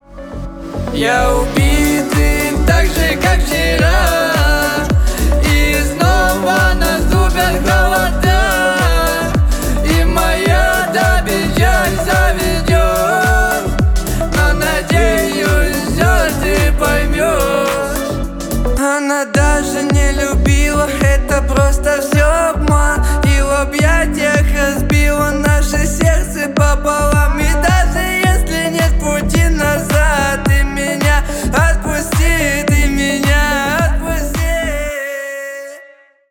Рэп и Хип Хоп
грустные